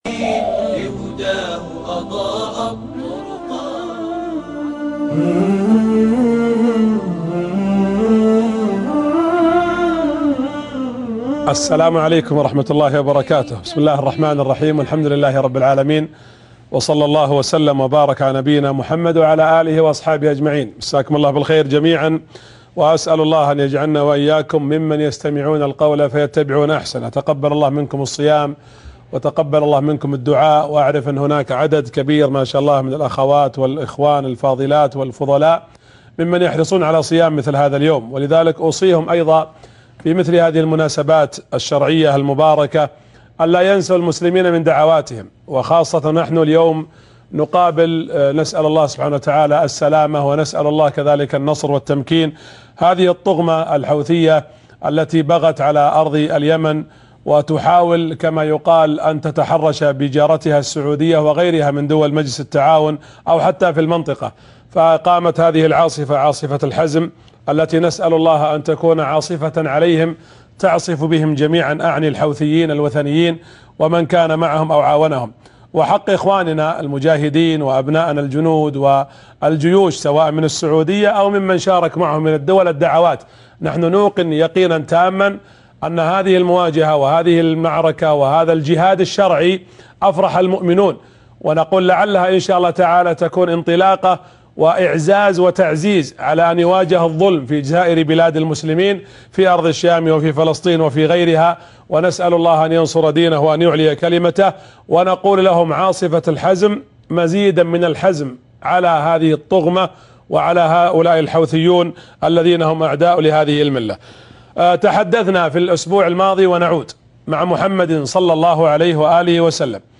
السيرة النبوية> الحلقة الخامسة درس السيرة النبوية